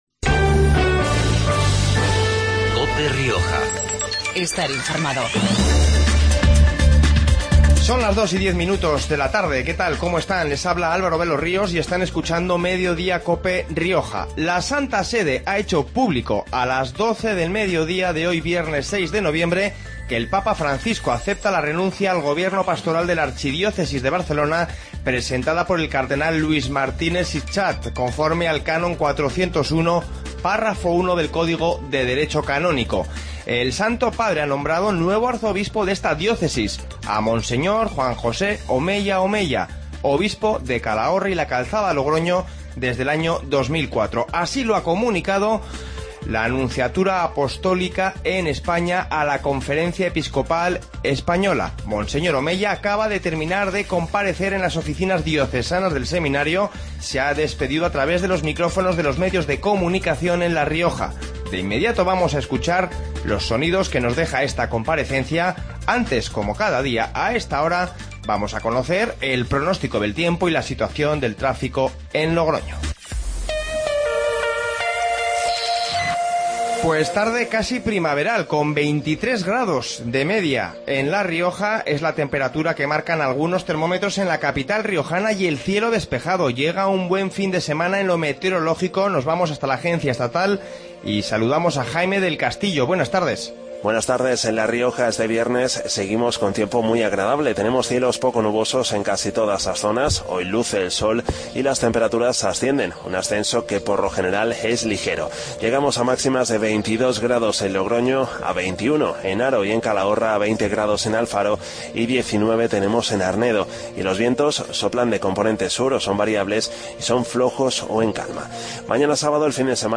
Informativo Mediodia en La Rioja 06-11-15